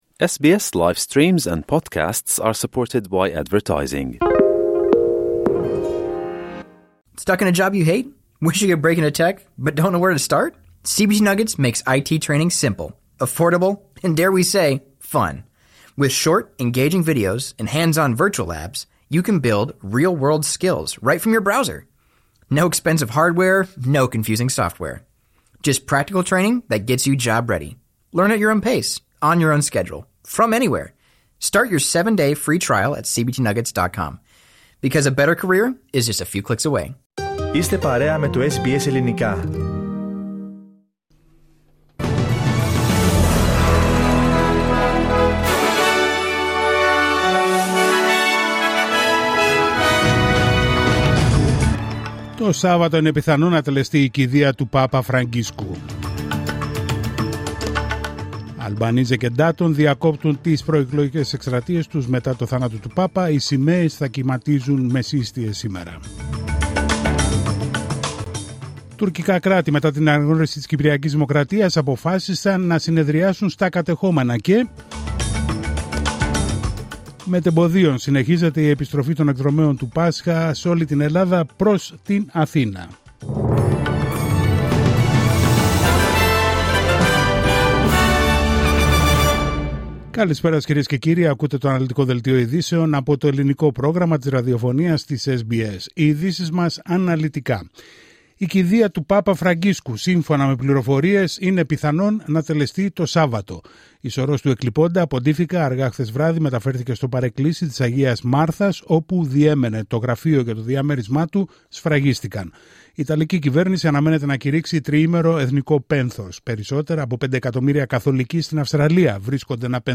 Δελτίο ειδήσεων Τρίτη 22 Απρίλιου 2025